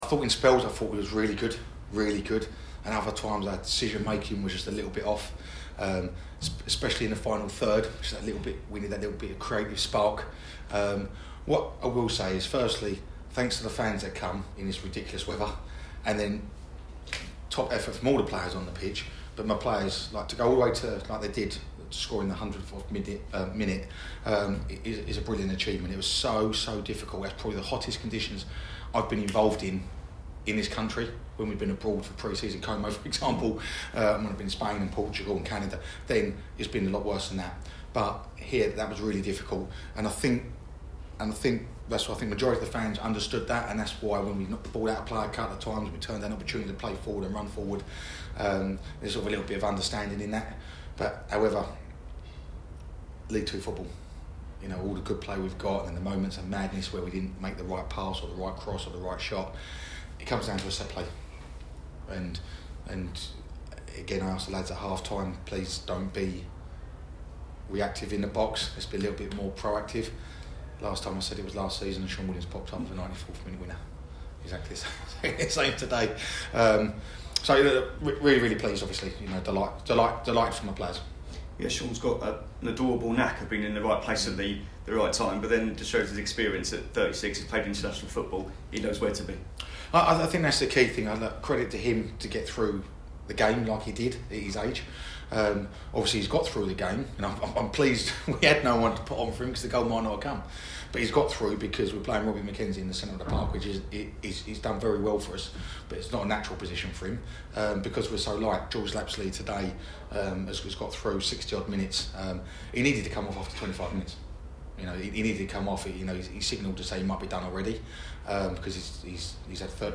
Listen: Manager Neil Harris reacts after Gillingham defeat Harrogate 1 - nil to go top of the League 2 table